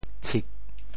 潮语发音
tshik4.mp3